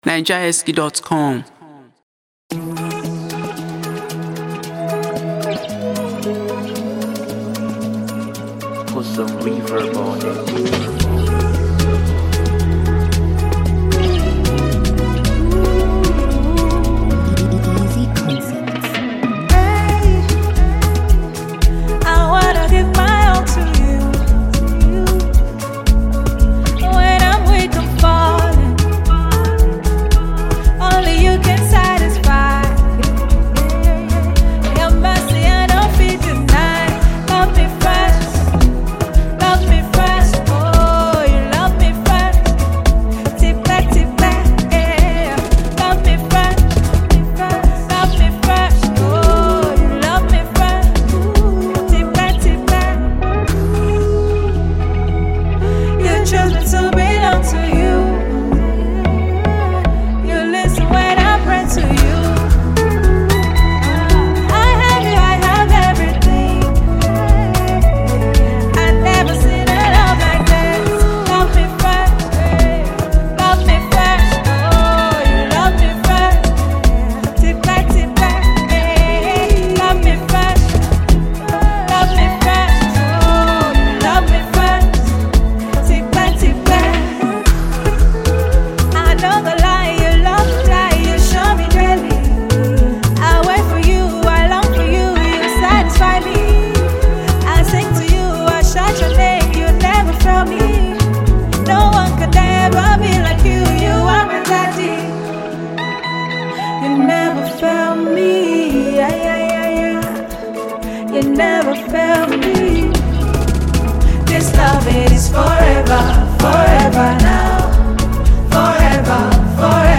A well-seasoned Nigerian female gospel singer
” a soul-lifting melody.